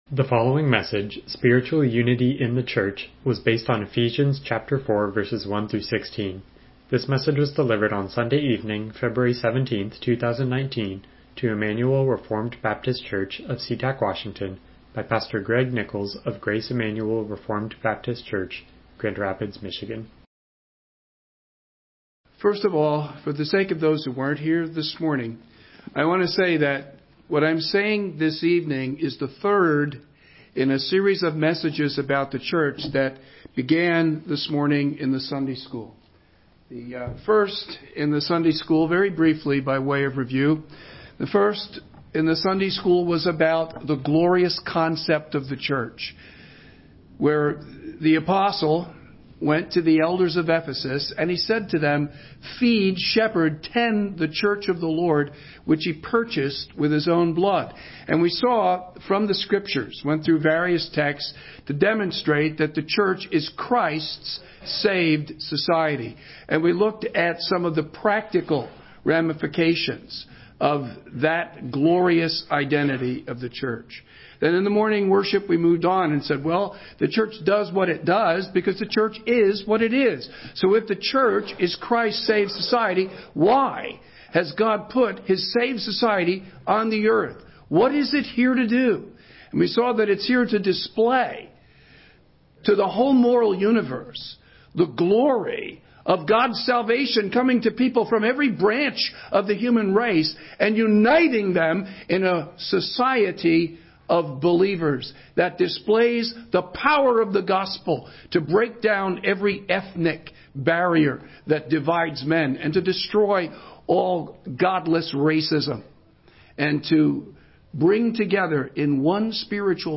Passage: Ephesians 4:1-16 Service Type: Evening Worship